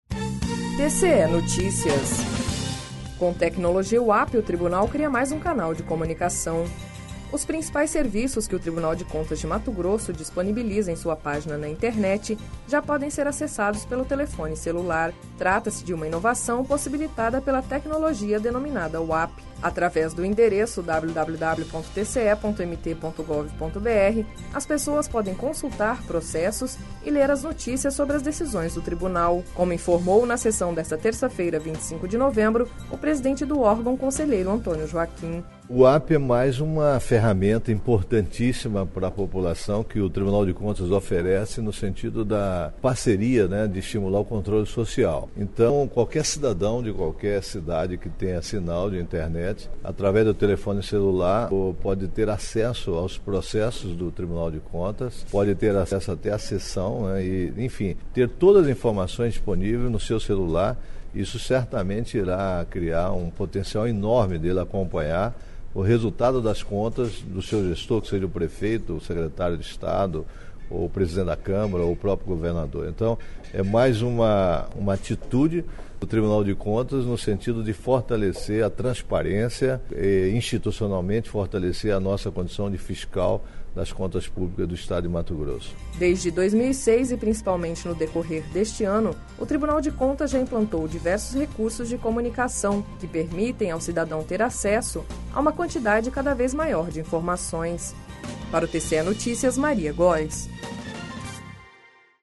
Sonora: Antonio Joaquim – conselheiro presidente do TCE-MT